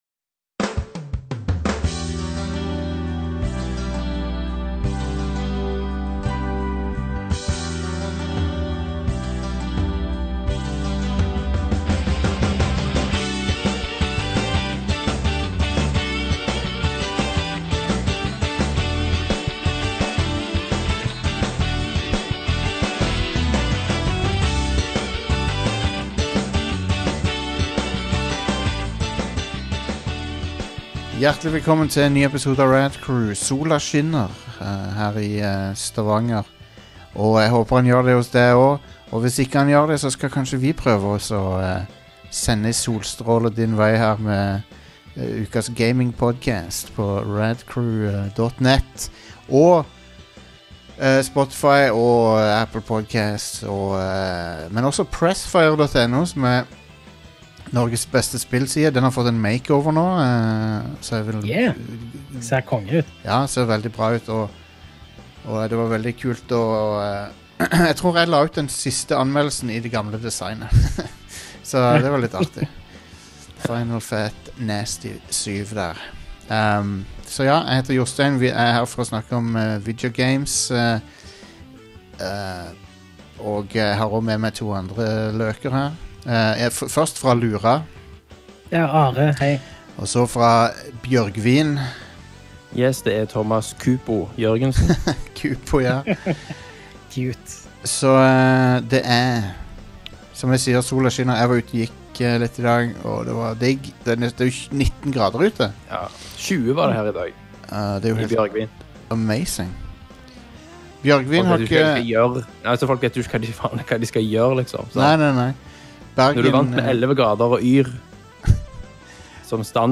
Vi ber om tålmodighet for enkelte tekniske utfordringer mens vi tilpasser oss.